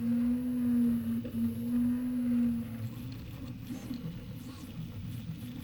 PrintingFilament2 1.wav